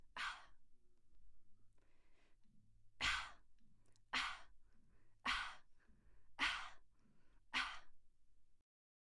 Beatbox图书馆 " 人类的小号 - 声音 - 淘声网 - 免费音效素材资源|视频游戏配乐下载
人体圈套声音为beatbox混音。